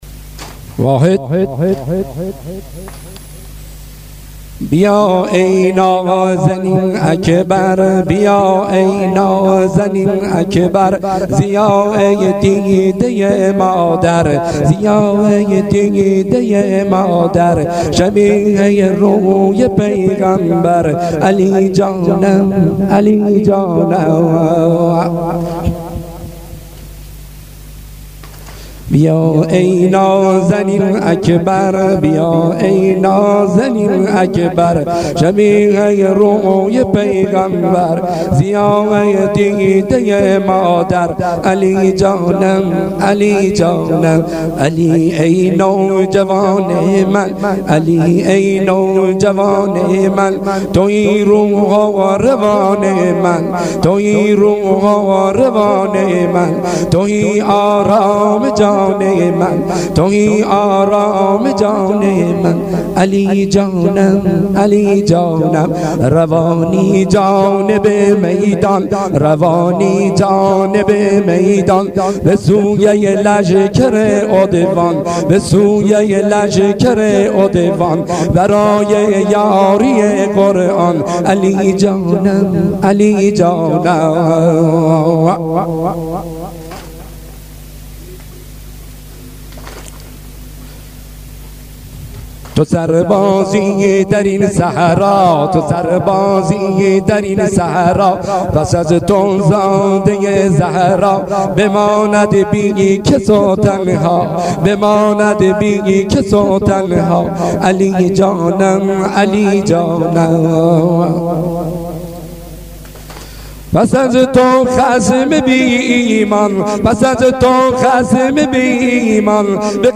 سینه زنی سنگین - سبک بهبهانی